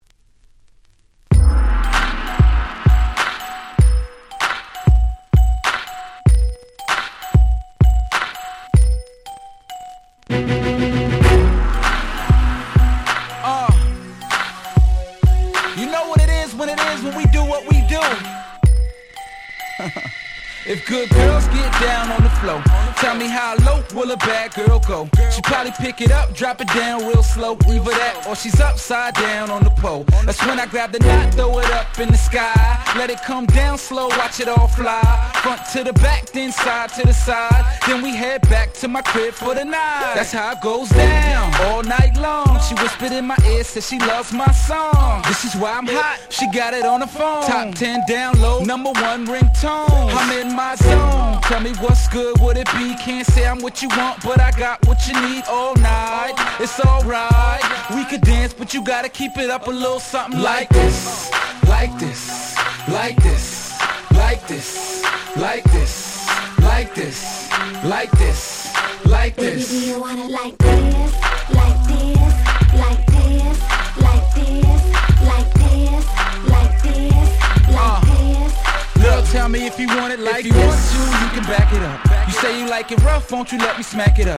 07' Smash Hit Hip Hop !!